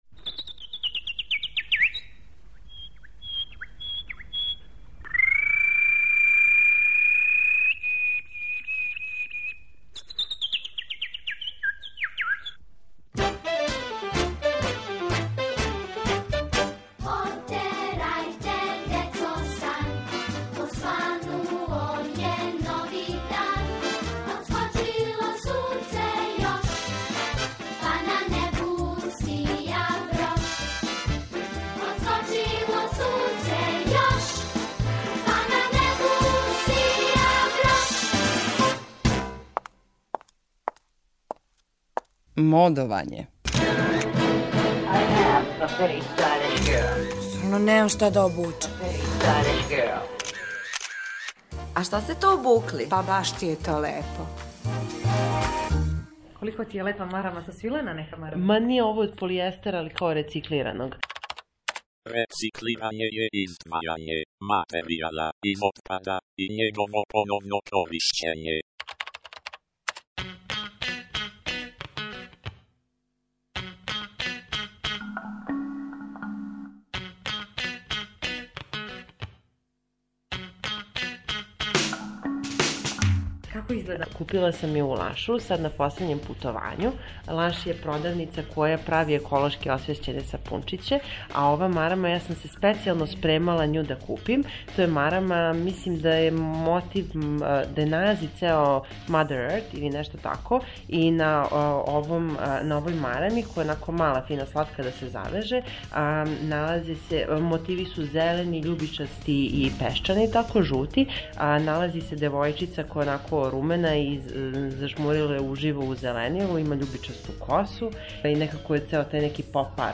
У серијалу МОДОВАЊЕ питамо занимљиво одевене случајне пролазнике да опишу своју одевну комбинацију.